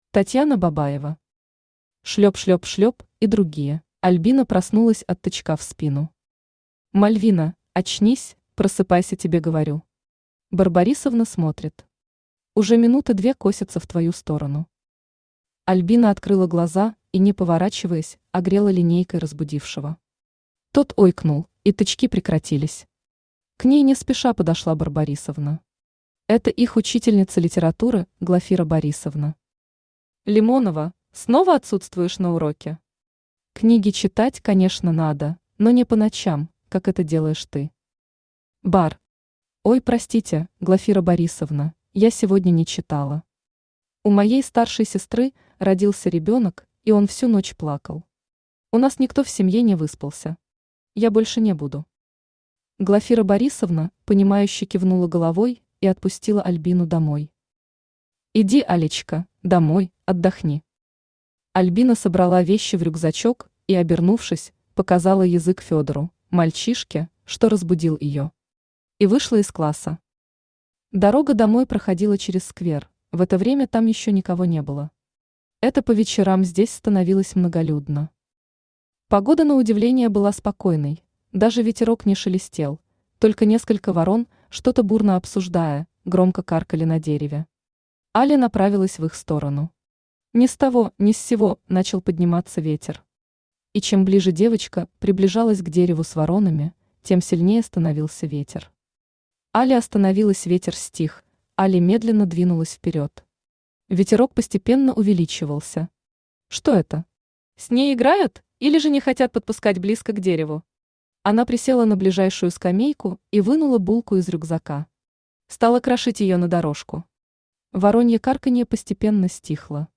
Аудиокнига Шлеп-шлеп-шлеп и другие | Библиотека аудиокниг
Aудиокнига Шлеп-шлеп-шлеп и другие Автор Татьяна Бабаева Читает аудиокнигу Авточтец ЛитРес.